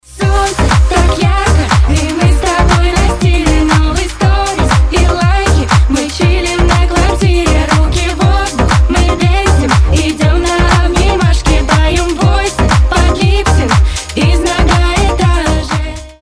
Танцевальные рингтоны
Позитивные